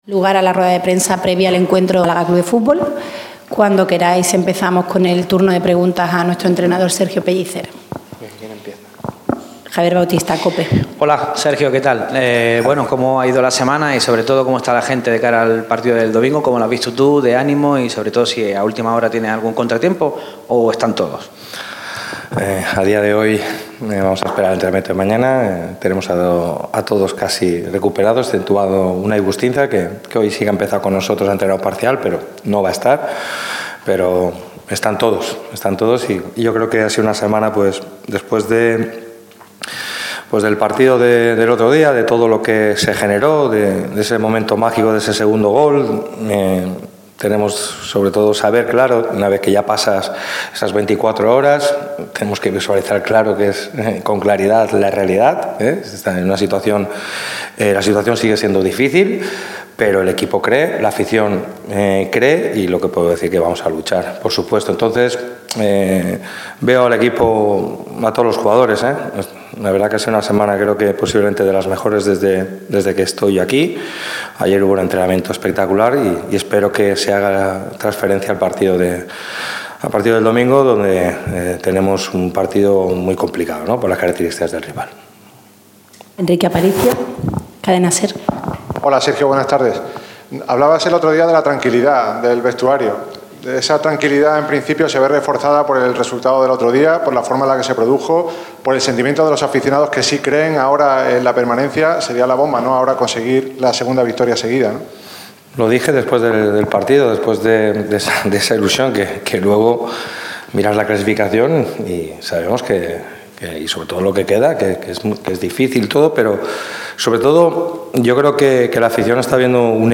El técnico del Málaga ha hablado en rueda de prensa en las horas previas al choque ante el Andorra, que será el domingo a las 18:30 horas.